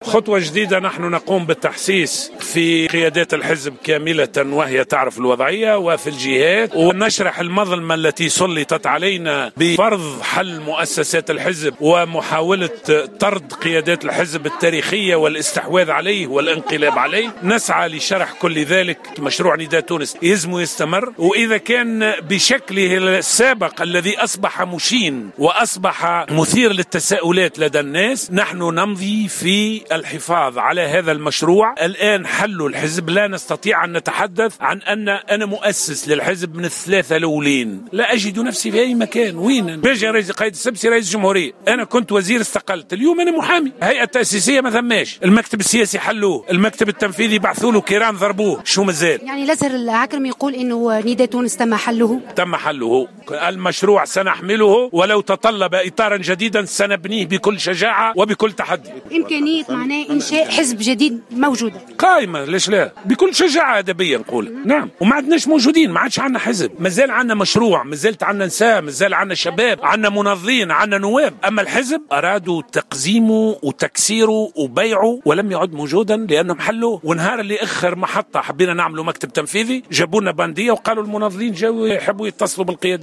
وجاءت تصريحات العكرمي على هامش اجتماع في الحمامات نظمته القيادات المنشقة عن الحزب.